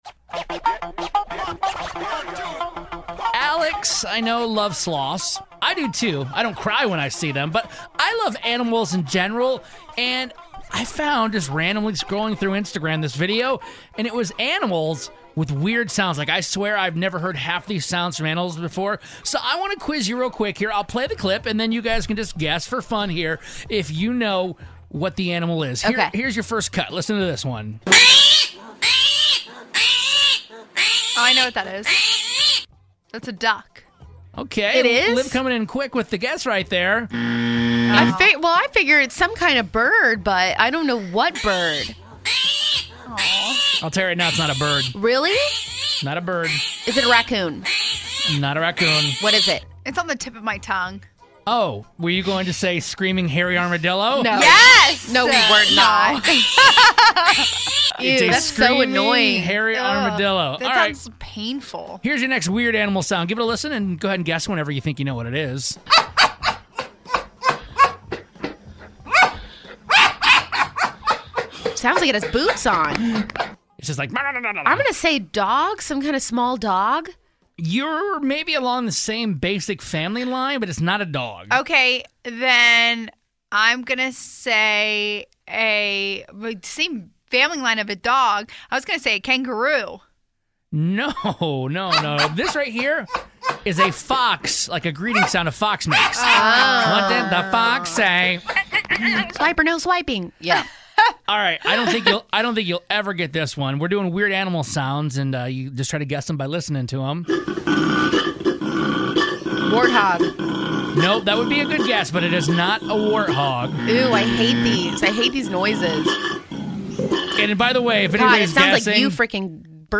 Weird Animal Sounds